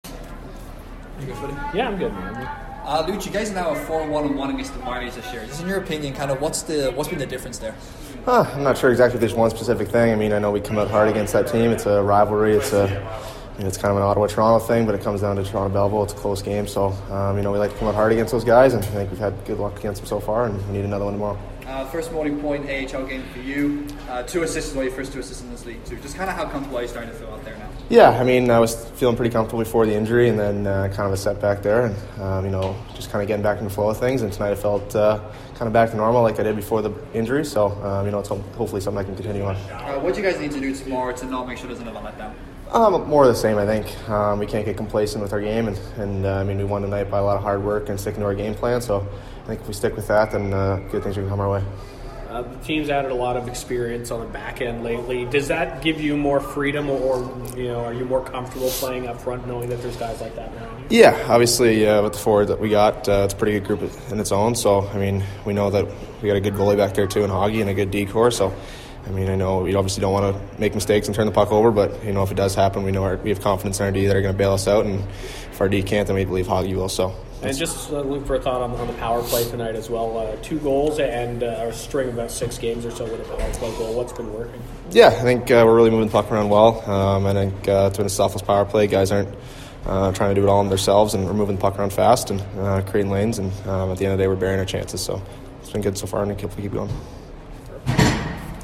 Post Game Interview: